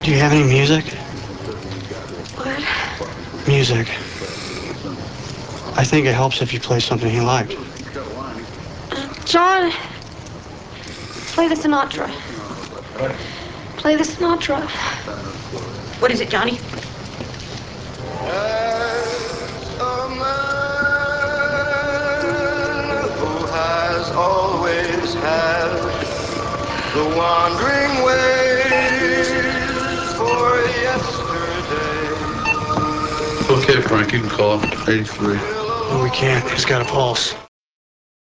FRANCK PIERCE - Nicolas Cage / MARY BURKE - Patricia Arquette
LARRY - John Goodman